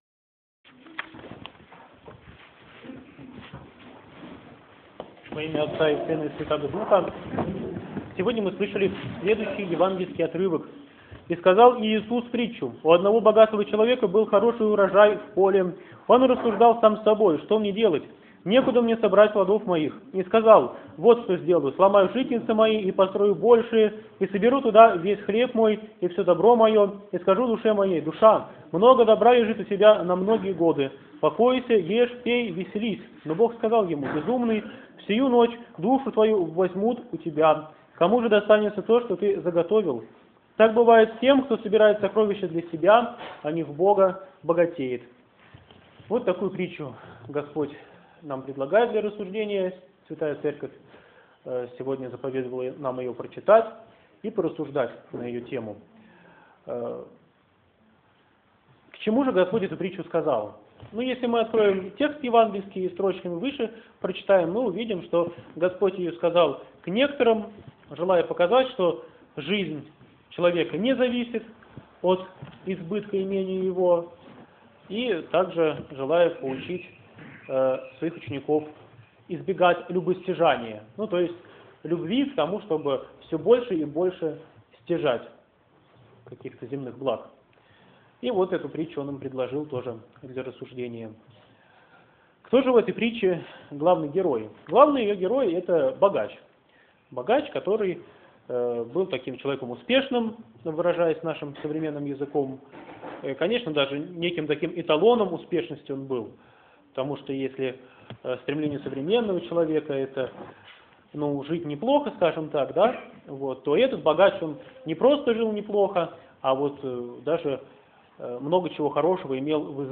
БОГОЛЮБСКИЙ ХРАМ ПОСЕЛОК ДУБРОВСКИЙ
Проповедь в Неделю 23 по Пятидесятнице, о безумном богаче 2013.mp3